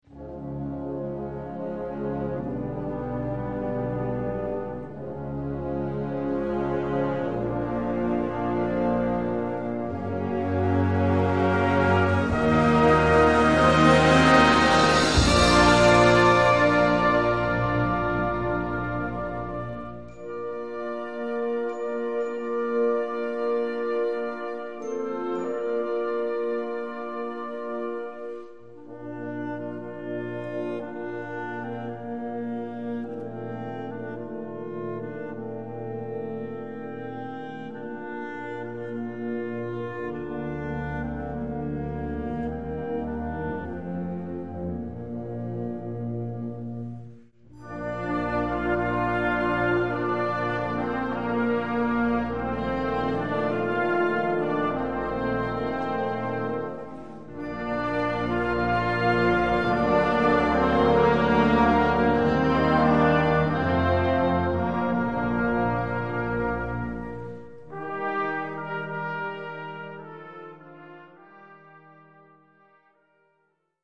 Gattung: piano duet (mittelschwer)
Besetzung: Instrumentalnoten für Klavier